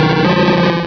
Cri de Kicklee dans Pokémon Rubis et Saphir.